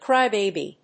音節crý・bàby 発音記号・読み方
/ˈkraɪˈbebi(米国英語), ˈkraɪˈbeɪbi:(英国英語)/